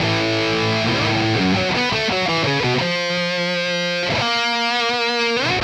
Index of /musicradar/80s-heat-samples/85bpm
AM_RawkGuitar_85-A.wav